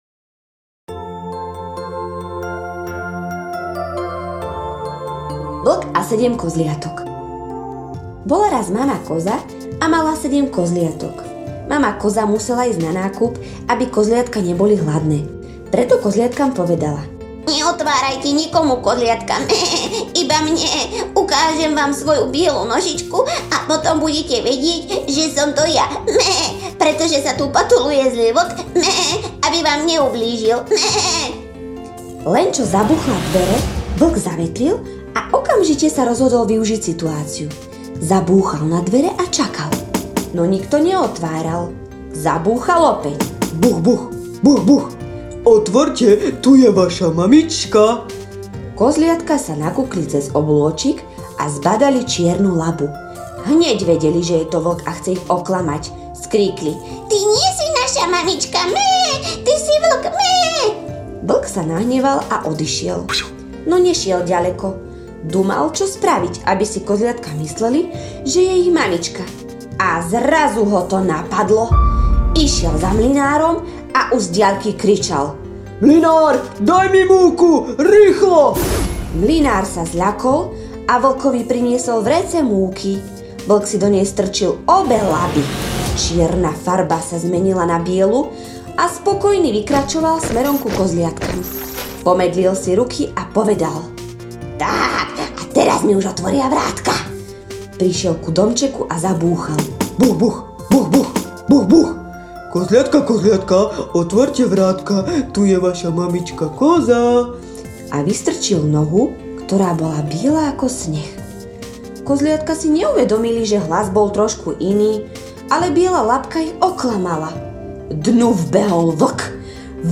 Ukážka audio rozprávky (Vlk a sedem kozliatok) ->